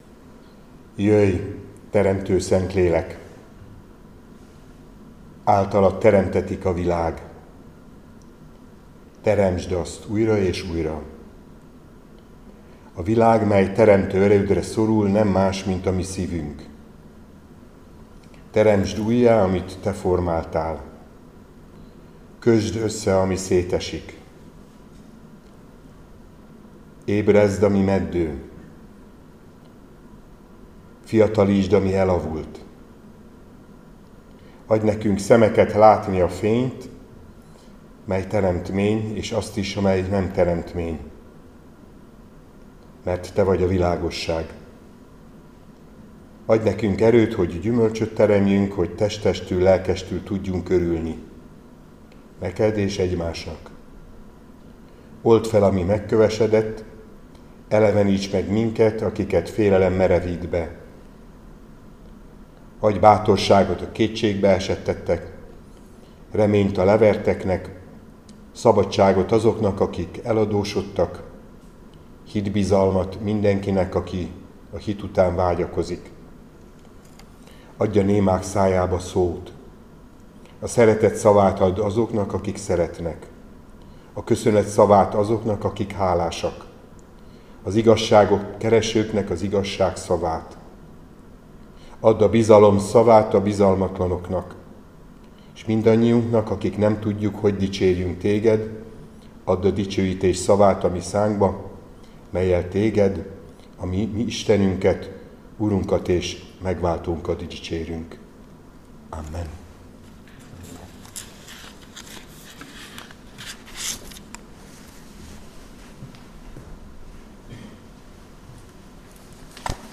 Áhítat, 2025. március 25.
Lk 19,1-10 Balog Zoltán püspök